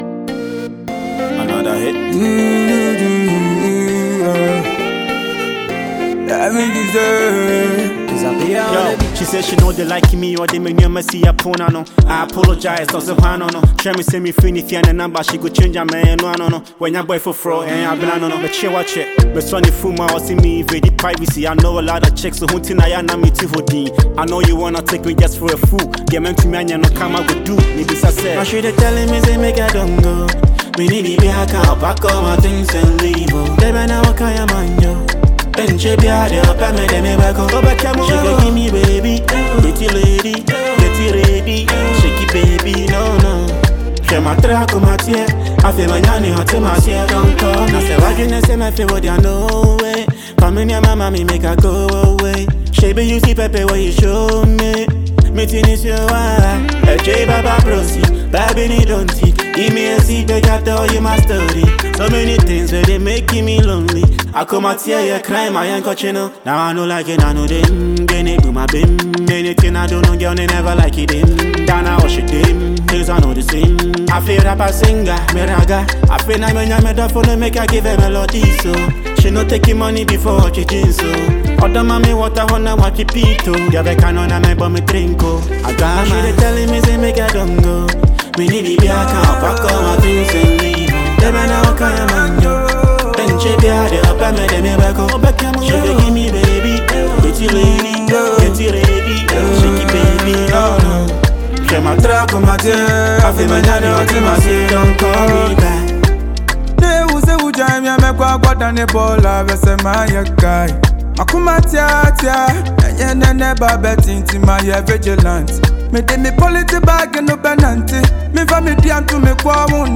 Ghanaian singer